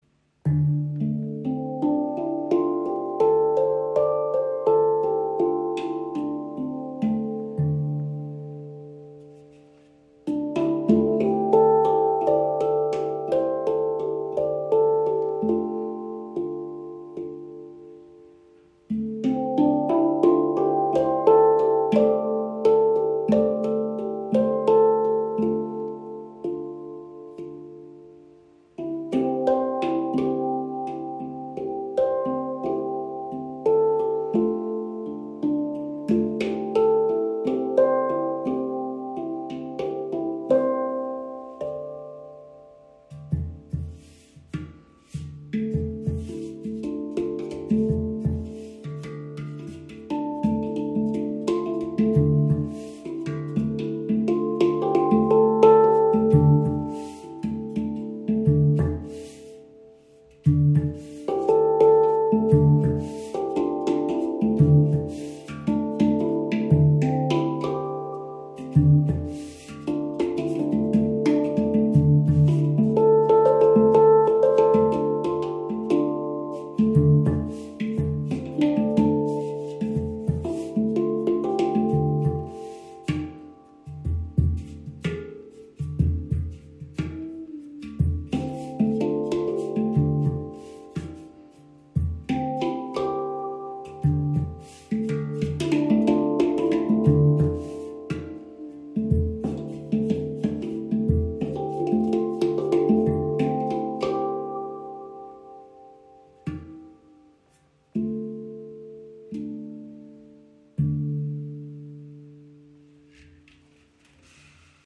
Handpan Chirp | D Amara | 10 Klangfelder – inkl. Rucksacktasche
• Icon D Amara – klingt immer harmonisch (D – A C D E F G A C D)
Chrip steht für klare Klänge, harmonische Skalen und ein sehr gutes Preis-Leistungs-Verhältnis – ideal für Einsteiger wie auch fortgeschrittene Spielerinnen und Spieler.
Selbst zufällig gespielte Melodien wirken mystisch, meditativ und leicht, oft als „fröhlich-melancholisch“ beschrieben.
Im Spiel zeigt sich die Handpan warm, klar und lebendig, mit angenehmer Dynamik und leichter Ansprache.